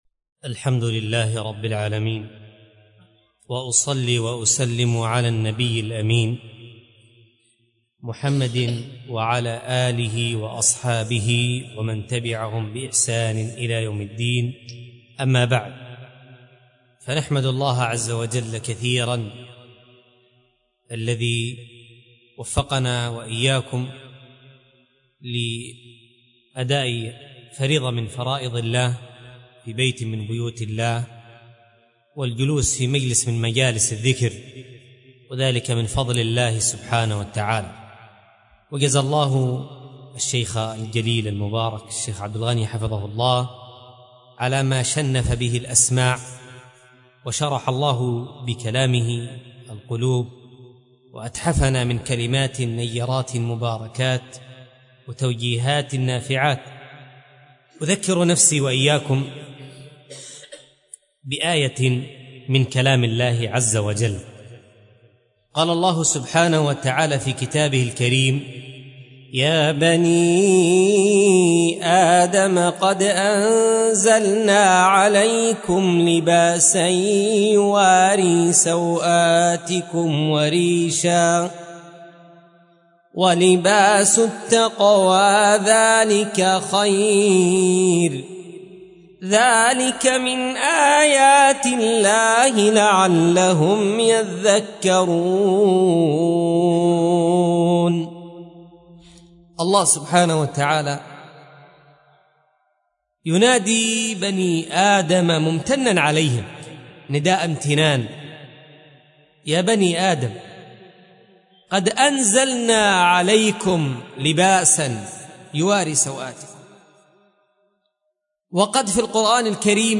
مسجد عمر بن الخطاب / الاربعين - القاهرة - مصر